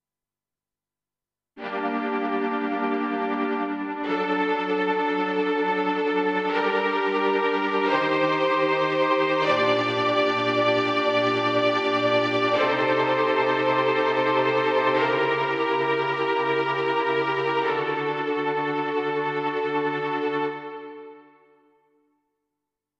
15. I SUONI - GLI STRUMENTI XG - GRUPPO "STRINGS"
05. Trem Strings
XG-05-05-TremStrings.mp3